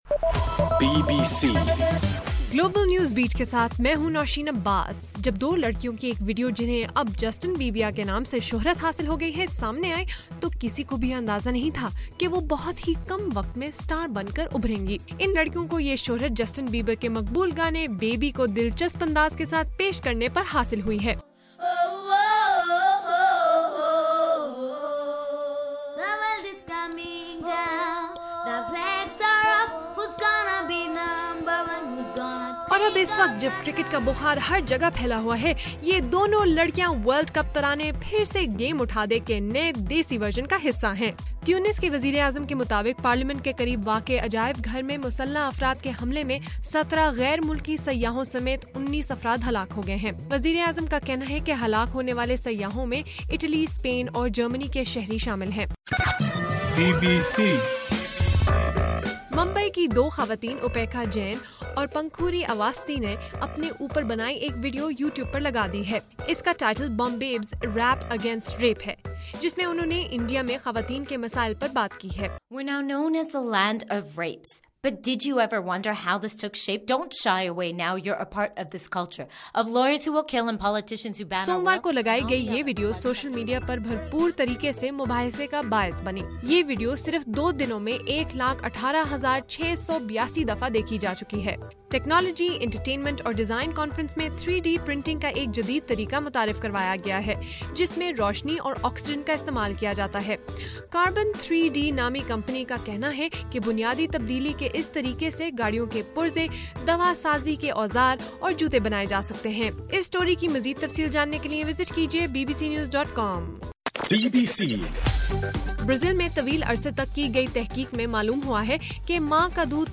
مارچ 18: رات 12 بجے کا گلوبل نیوز بیٹ بُلیٹن